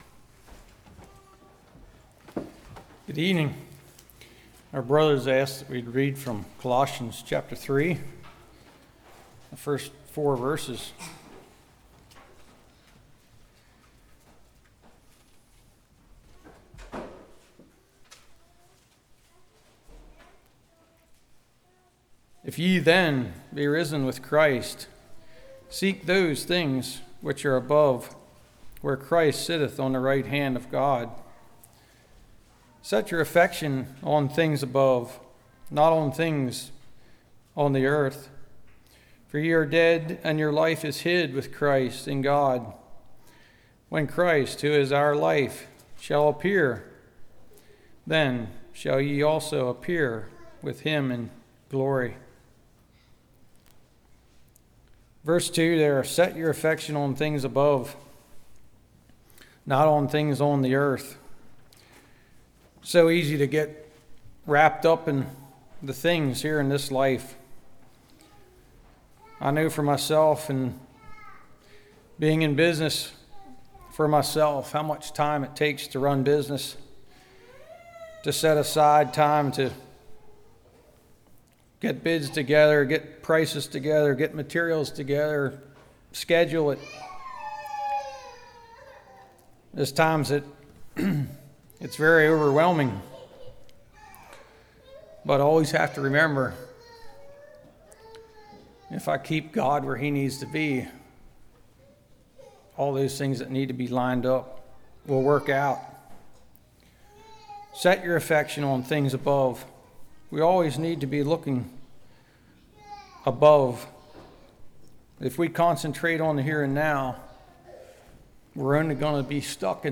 Colossians Series Passage: Colossians 3:1-4 Service Type: Evening Looking Back Looking Up Looking Ahead « BVS